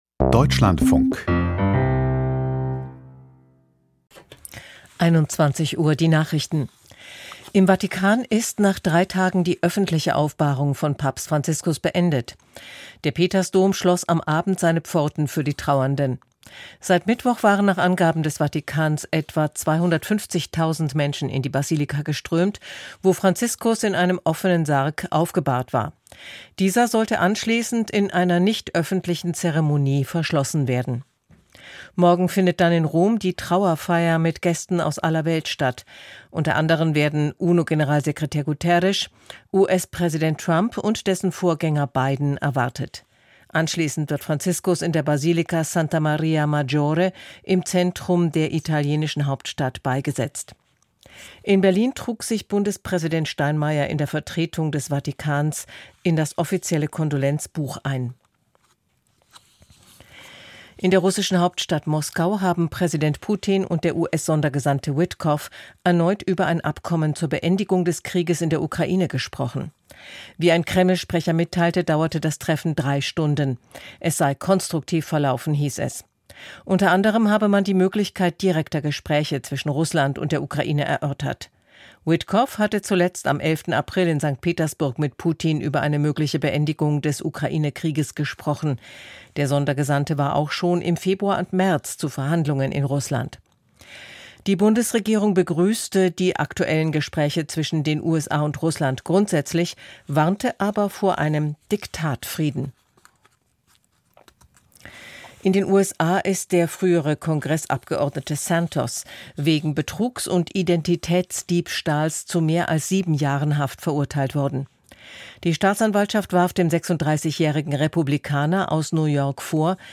Die Deutschlandfunk-Nachrichten vom 25.04.2025, 21:00 Uhr